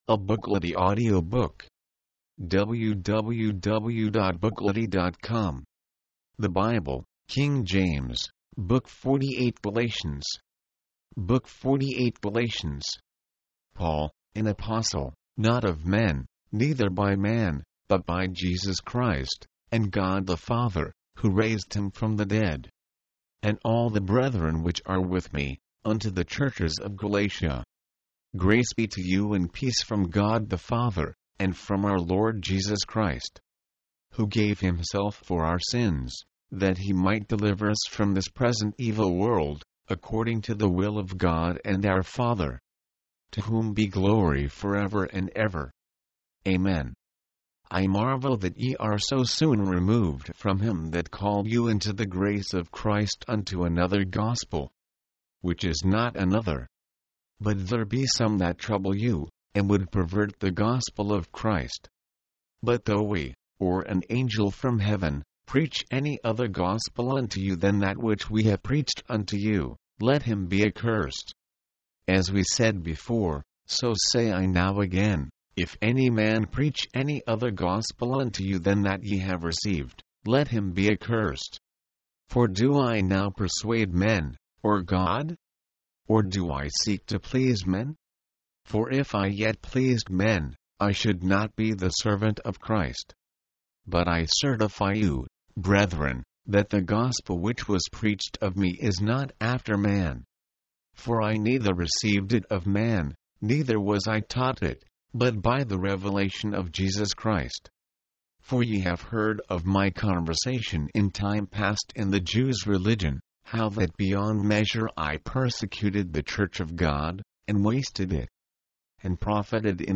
Galatians The Holy Bible: King James Version mp3, audiobook, audio, book Date Added: Dec/31/1969 Rating: 2.